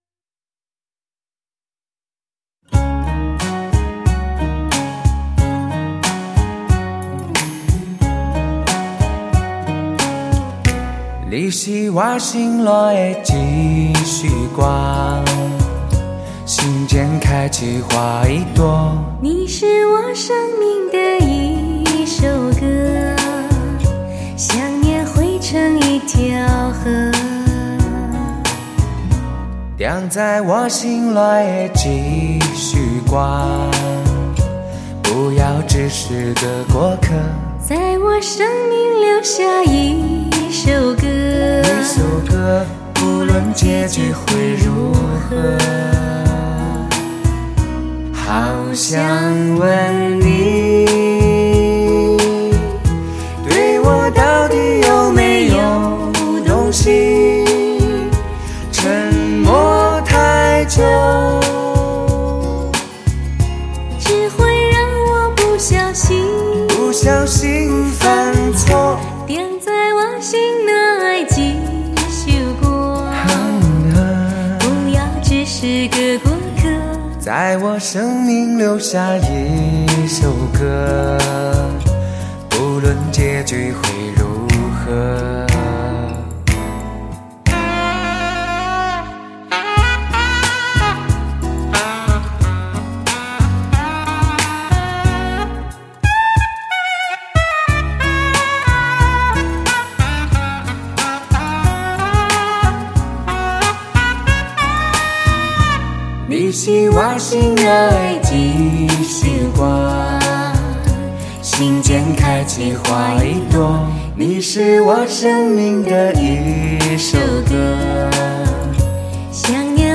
超级Hi-Fi 煲机CD